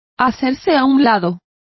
Complete with pronunciation of the translation of sidestepped.